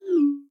ReceivedMessage.mp3